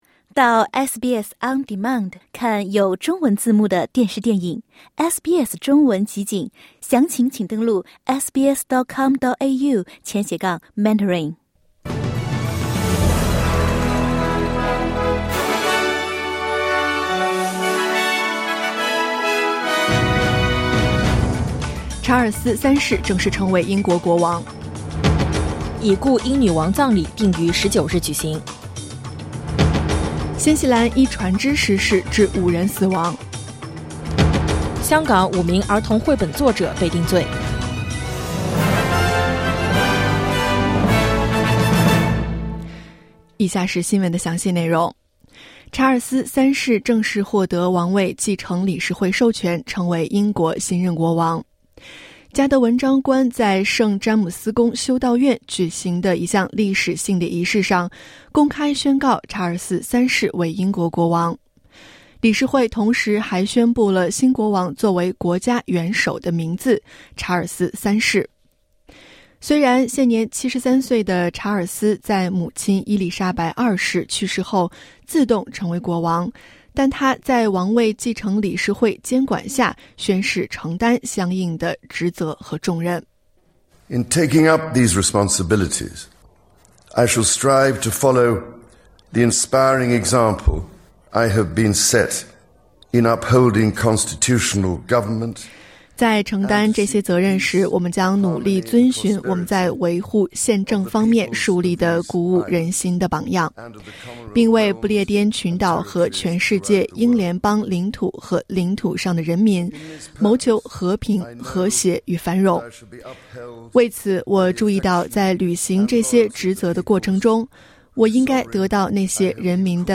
SBS早新闻（2022年9月11日）